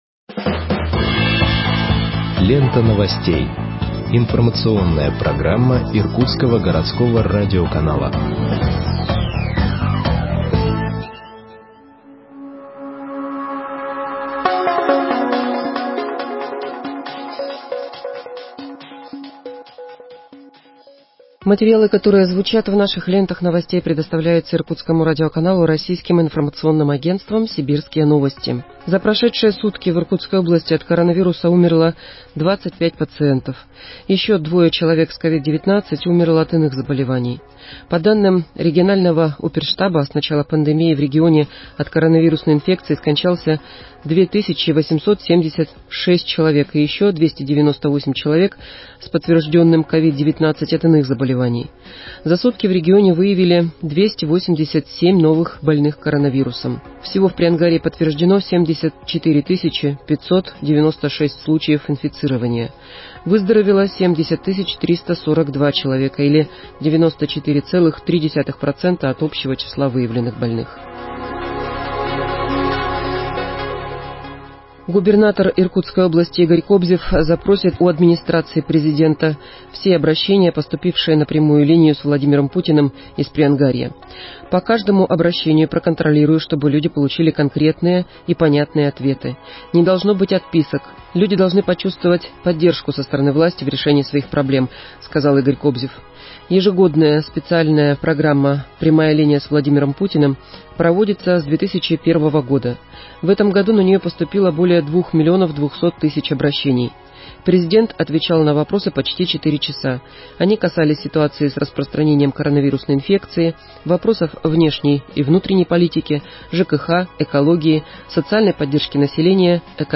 Выпуск новостей в подкастах газеты Иркутск от 01.07.2021 № 2